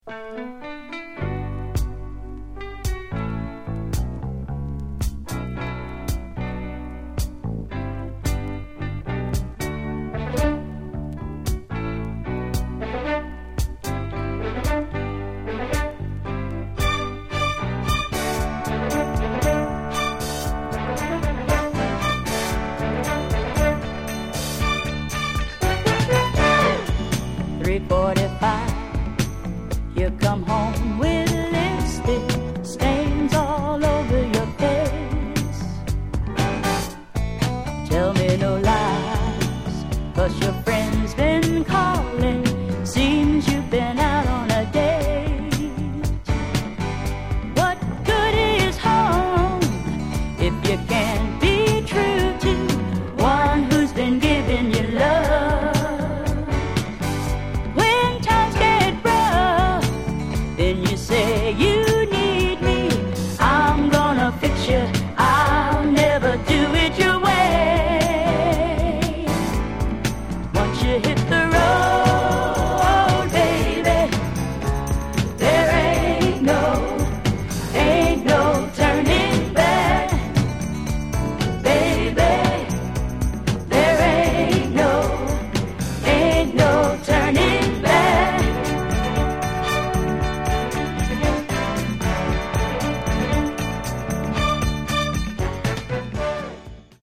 Genre: Northern Soul, Philly Style
but for some reason this gorgeous slice of Philadelphia Soul